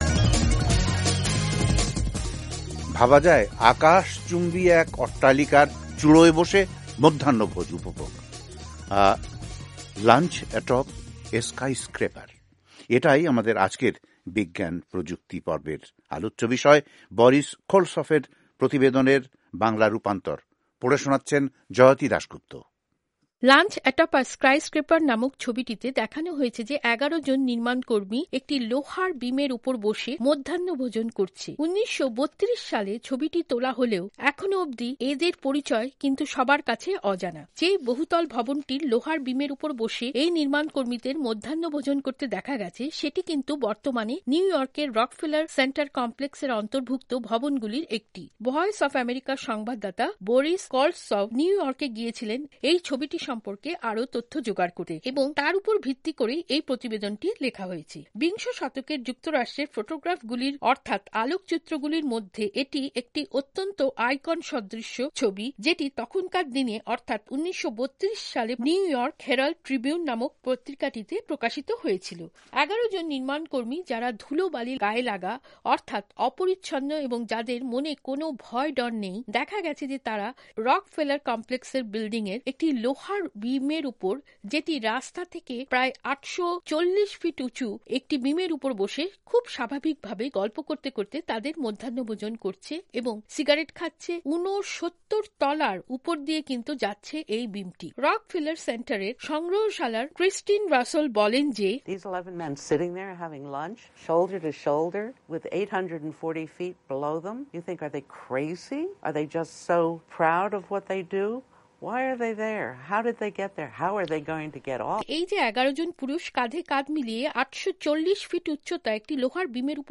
বেতার